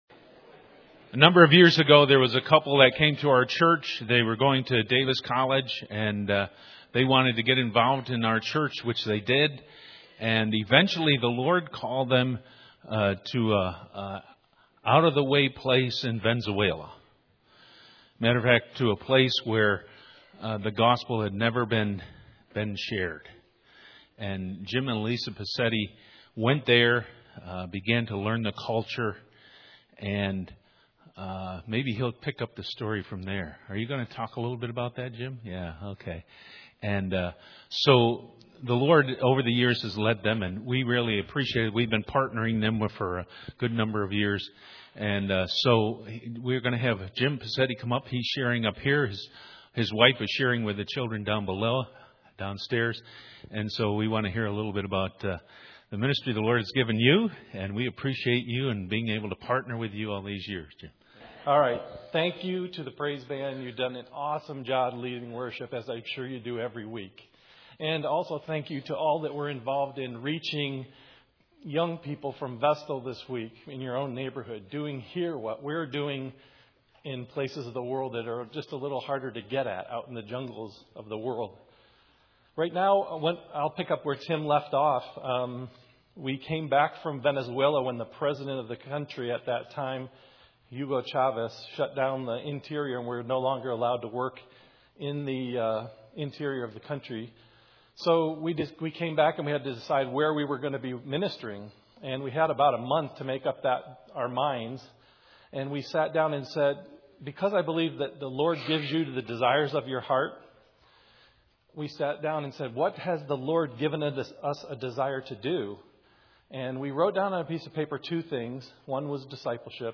The sermon begins at the 17 minute 40 second mark